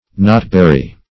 Search Result for " knotberry" : The Collaborative International Dictionary of English v.0.48: Knotberry \Knot"ber`ry\, n. (Bot.) The cloudberry ( Rudus Cham[ae]morus ); -- so called from its knotted stems.